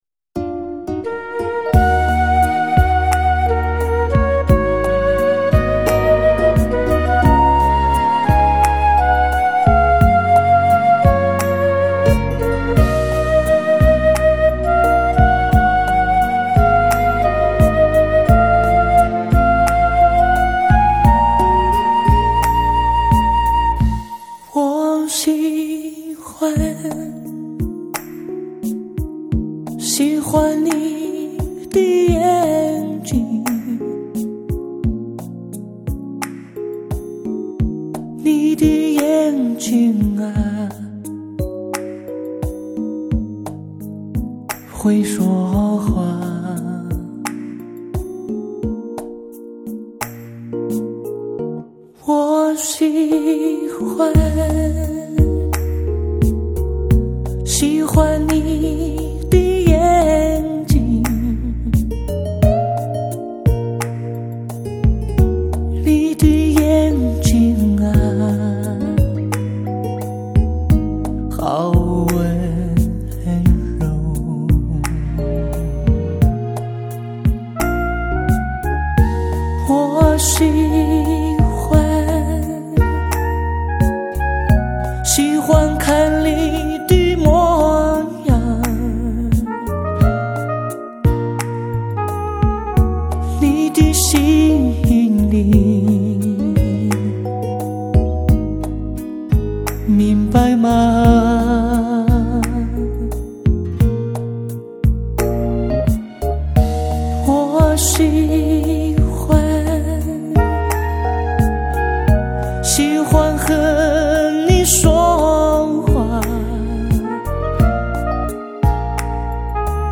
真挚的情感抒情歌曲